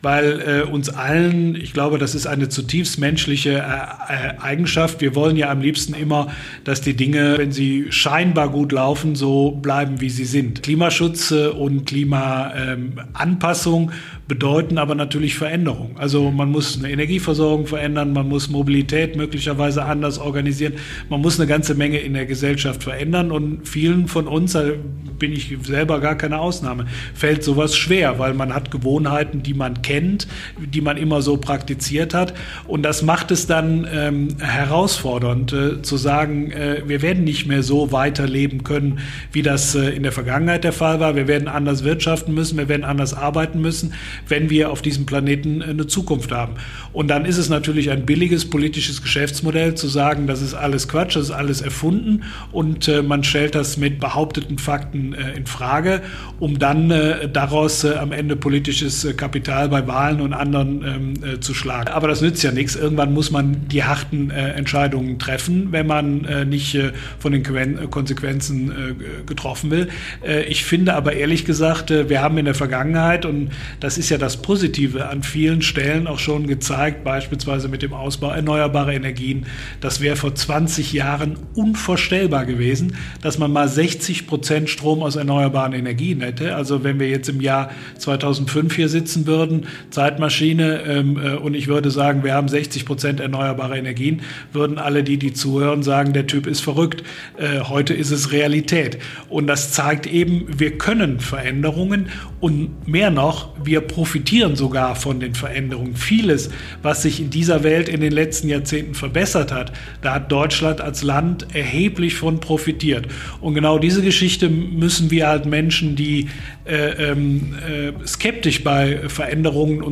Oliver Krischer (Bündnis90/Die Grünen) ist Minister für Umwelt, Naturschutz und Verkehr in NRW. Gemeinsam sprechen wir im Detail über die größten Probleme und die besten Lösungen im Bereich Umwelt und Naturschutz. Dabei muss der Minister sich auch einem Vogelquiz stellen!